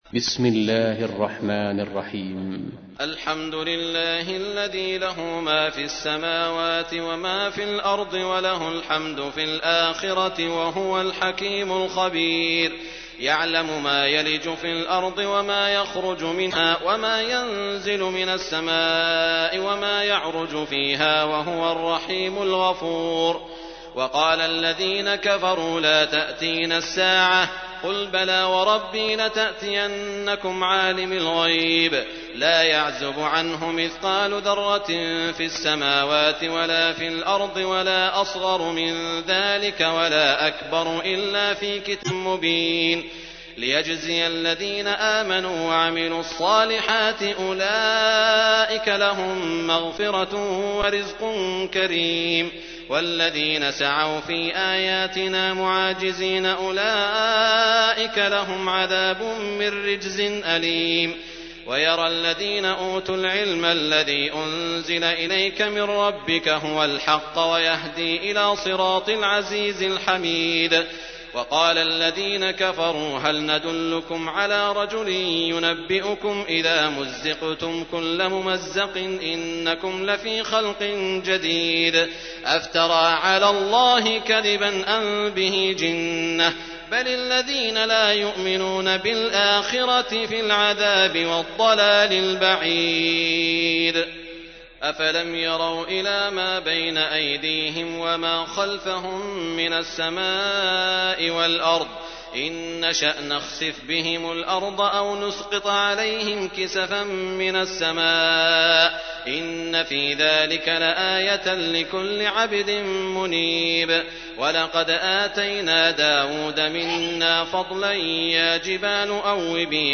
تحميل : 34. سورة سبأ / القارئ سعود الشريم / القرآن الكريم / موقع يا حسين